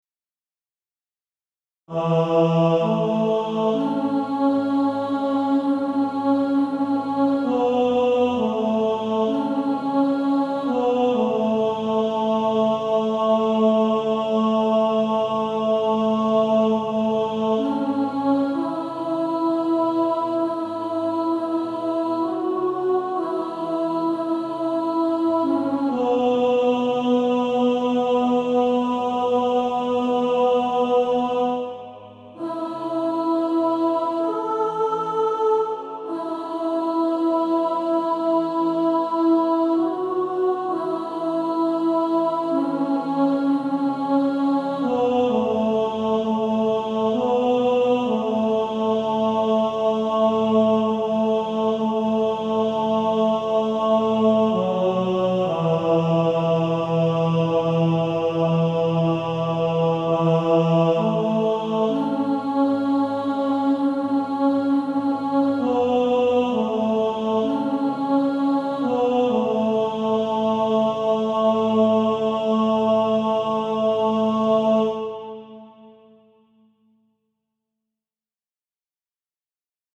Author: African-American spiritual